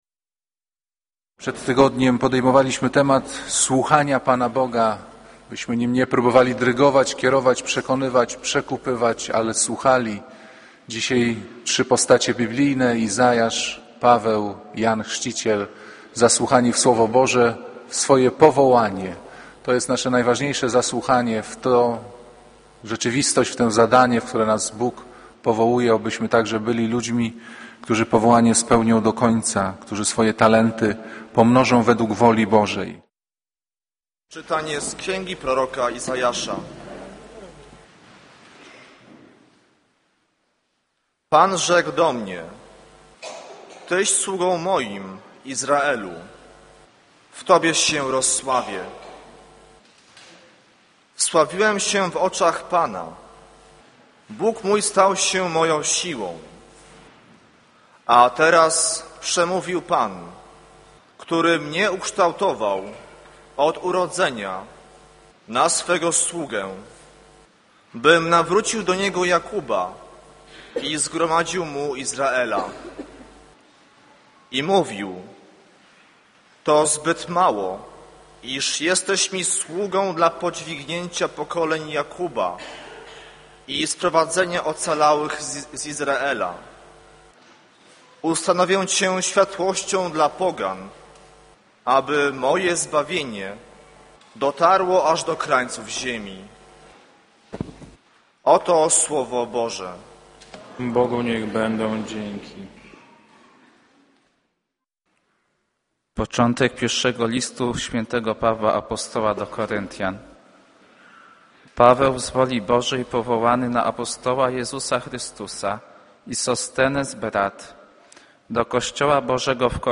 Kazanie z 13 stycznia 2008r.